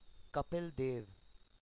pronunciation) (born 6 January 1959), better known as Kapil Dev, is a former Indian cricketer.